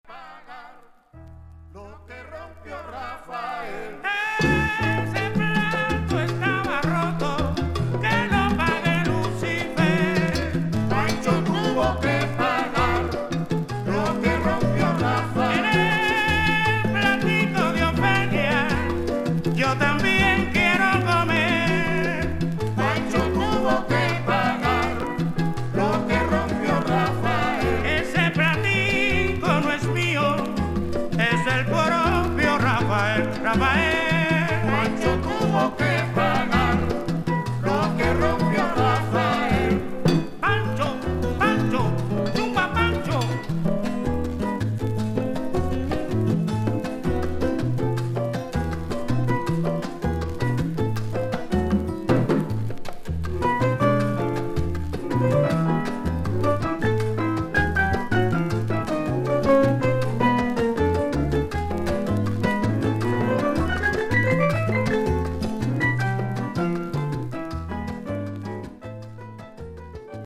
ワラーチャ・ルンバの男性ボーカリスト、ソネーロ、ルンベーロ
キューバの（コンテンポラリー）ソンの味わい濃厚な９曲収録された傑作!!!